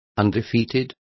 Complete with pronunciation of the translation of undefeated.